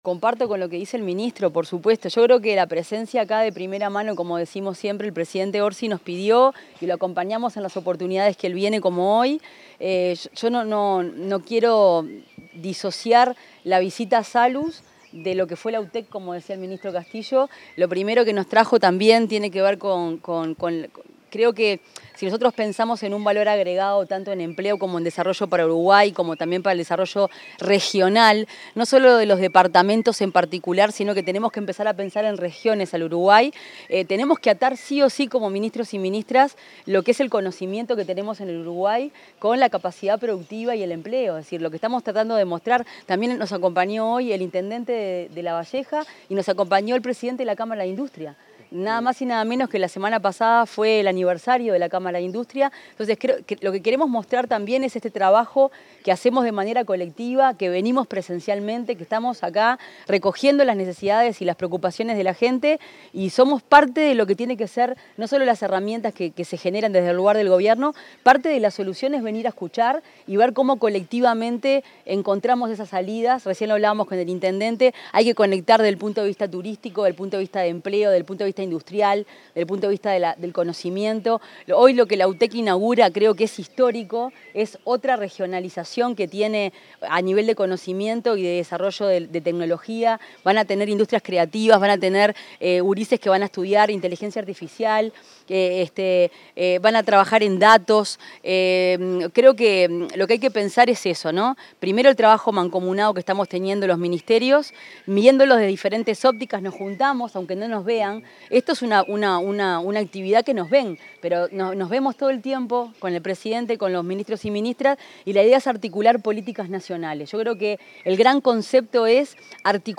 Declaraciones de ministra de Industria, Fernanda Cardona
Declaraciones de ministra de Industria, Fernanda Cardona 17/11/2025 Compartir Facebook X Copiar enlace WhatsApp LinkedIn Tras una visita a la planta de producción de bebidas de la empresa Salud en Minas, la ministra de Industria, Energía y Minería, Fernanda Cardona, dialogó con la prensa sobre la ampliación de la sede de la Universidad Tecnológica en Minas.